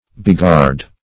Beghard \Be*ghard"\ Beguard \Be*guard"\, n. [F. b['e]gard,